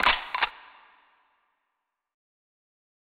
Metro Perc 2.wav